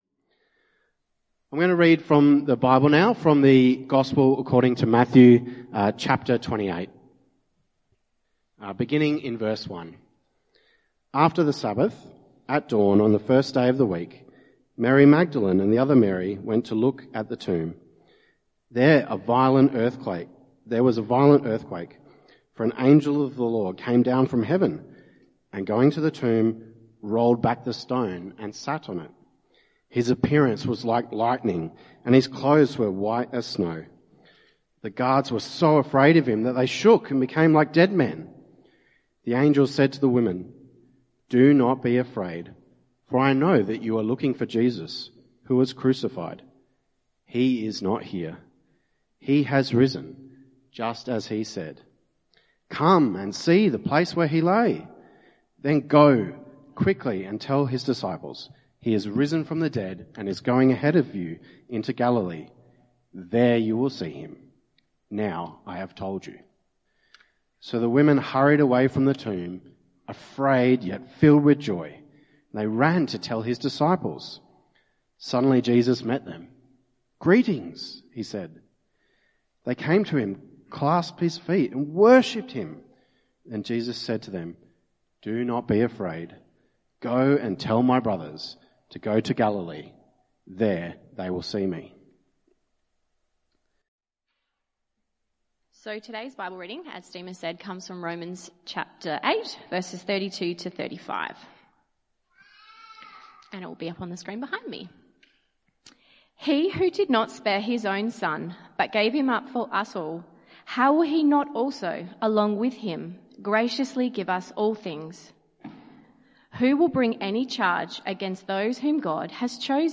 This Bible talk centres on the resurrection of Jesus as the foundation of true confidence.